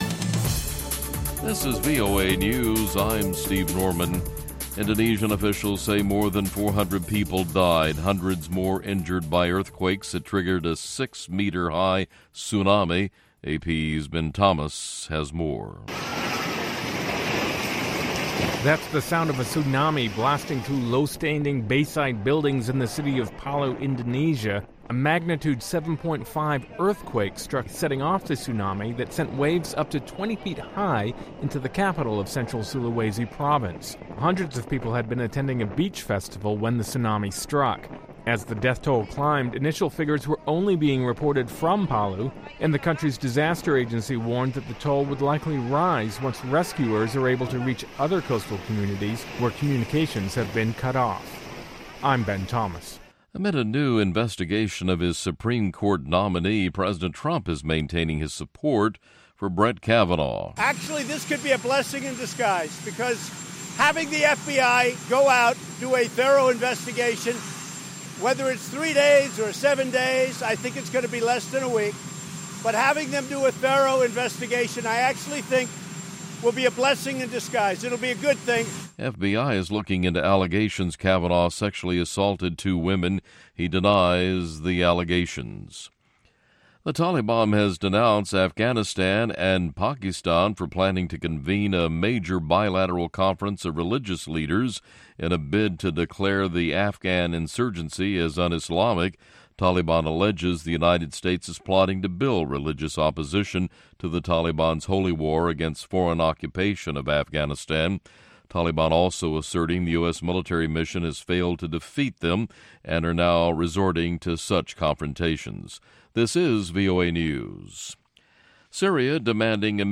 This exciting episode features new and old music from Guinea, Nigeria, Burkina Faso, Egypt, Ghana, Malawi, Sierra Leone and Liberia.